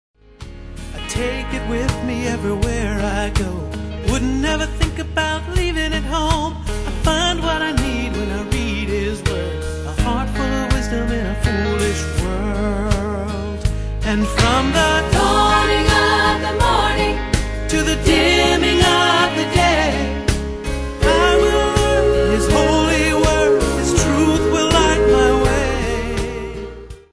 Country
The background vocals had already been finished.